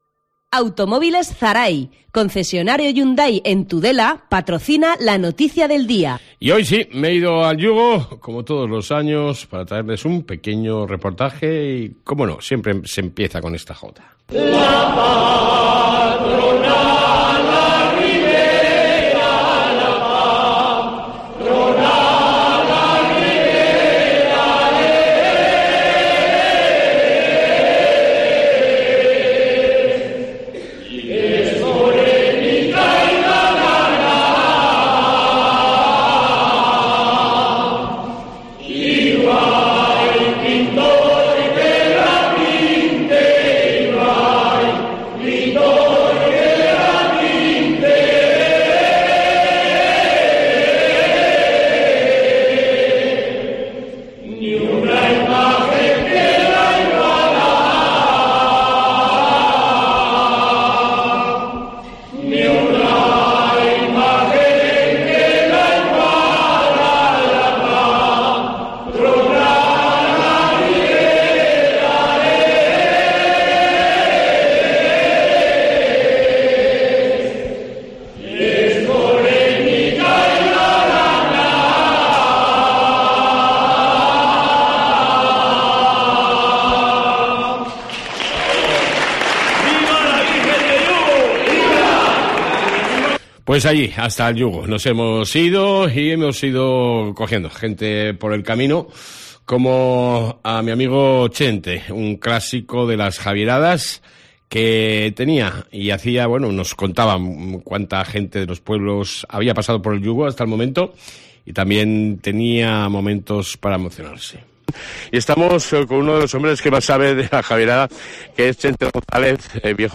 AUDIO: DESDE EL YUGO , CAMINANDO HACÍA JAVIER